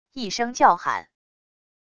一声叫喊wav音频